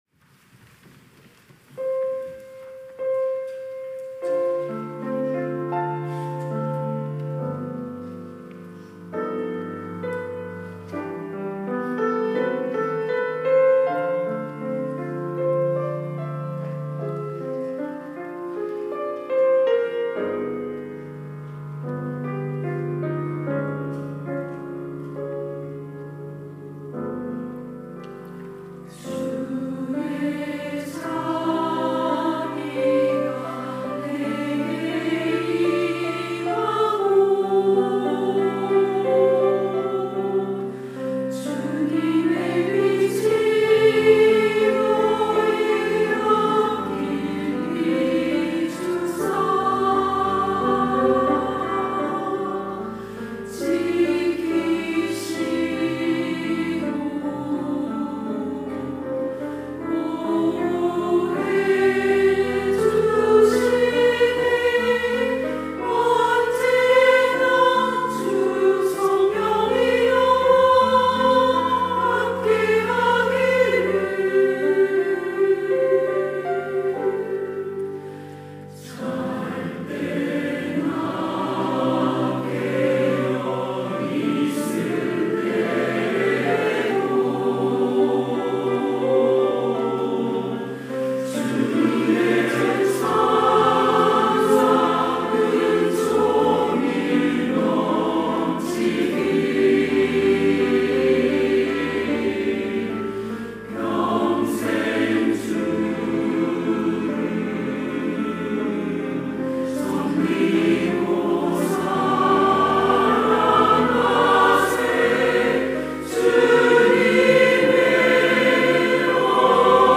시온(주일1부) - The Blessing(축복)
찬양대